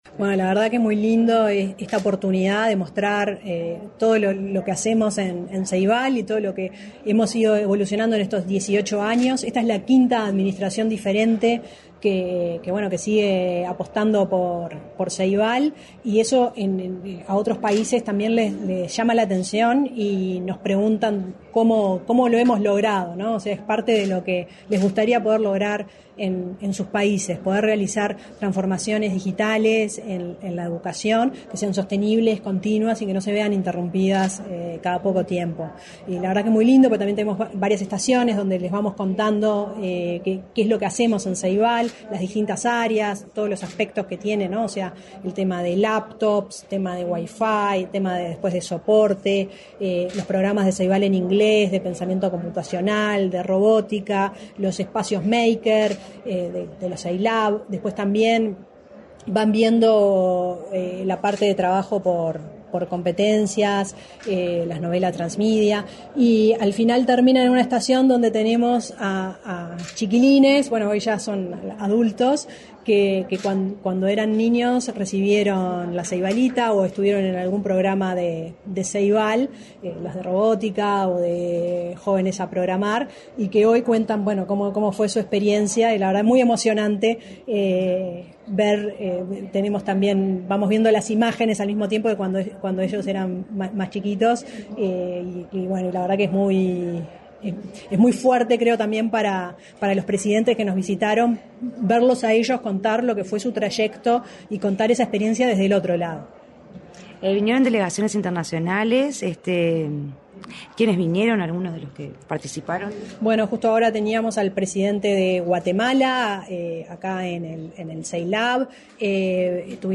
Entrevista a la presidenta de Ceibal, Fiorella Haim